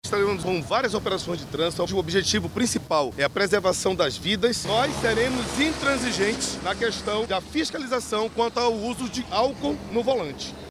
O secretário de Segurança Pública do Amazonas, Vinícius Almeida, disse que as fiscalizações atuam de forma severa, principalmente, no combate à embriaguez no trânsito, durante as festas de fim de ano.